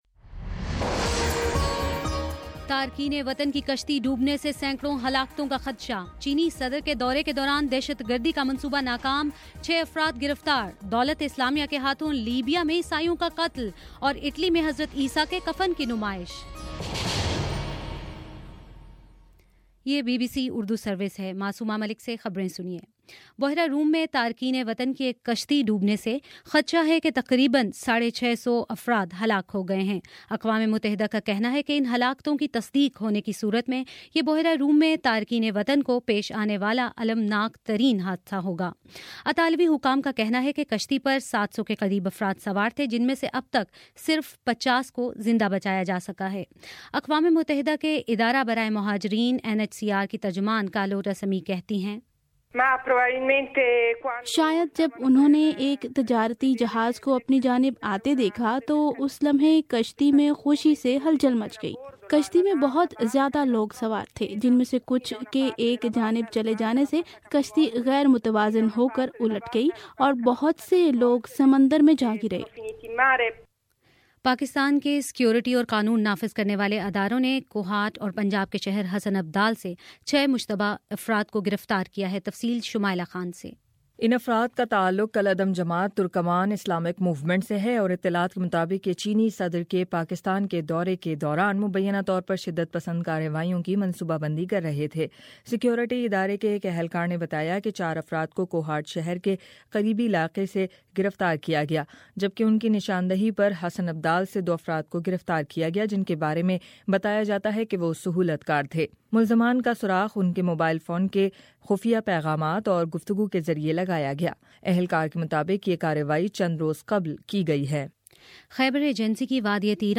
اپریل 19 : شام سات بجے کا نیوز بُلیٹن